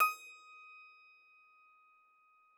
53l-pno19-D4.wav